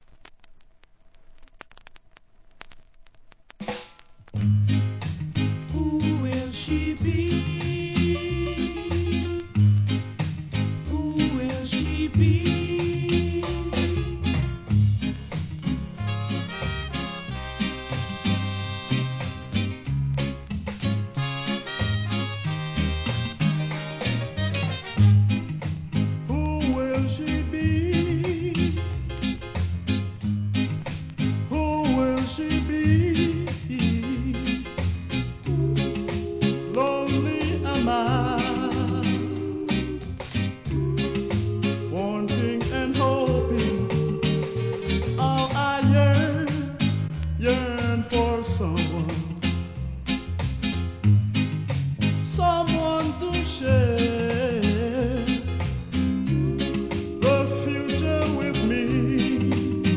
レアROCKSTEADY!!!